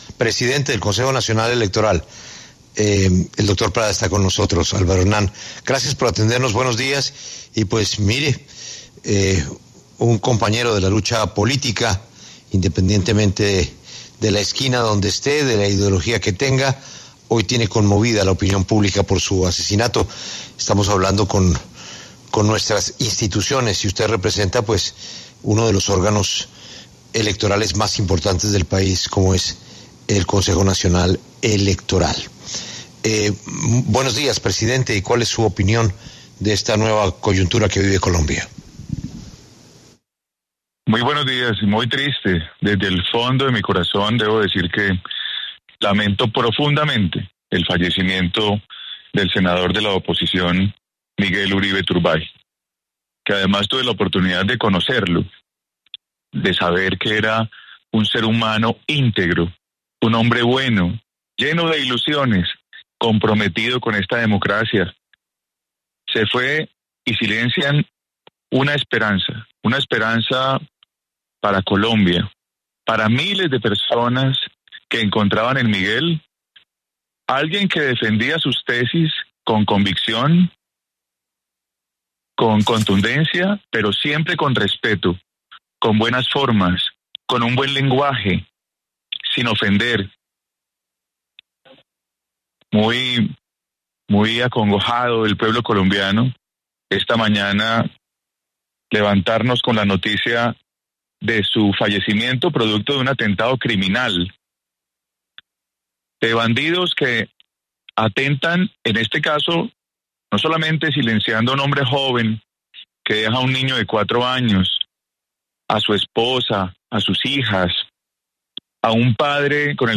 Álvaro Hernán Prada, presidente del Consejo Nacional Electoral (CNE), conversó con La W sobre el magnicidio contra Miguel Uribe Turbay.